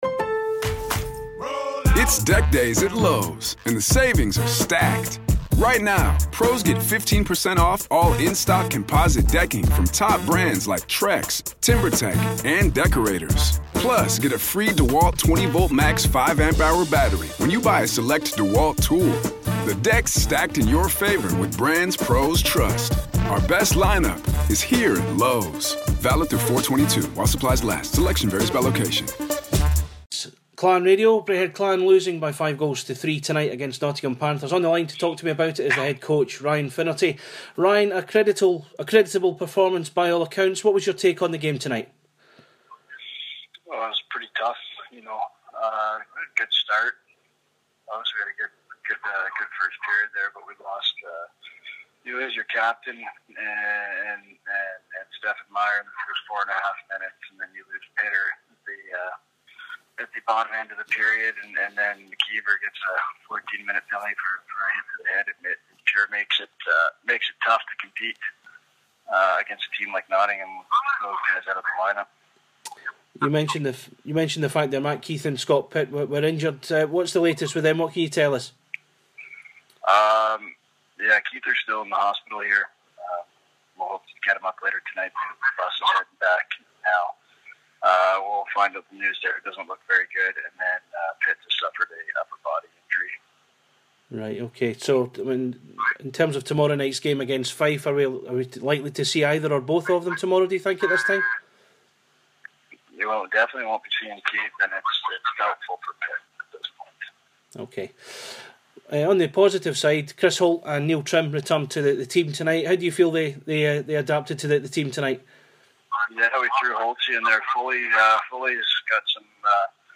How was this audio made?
POST MATCH